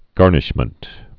(gärnĭsh-mənt)